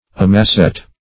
Meaning of amassette. amassette synonyms, pronunciation, spelling and more from Free Dictionary.
Search Result for " amassette" : The Collaborative International Dictionary of English v.0.48: Amassette \A`mas`sette"\, n. [F. See Amass .]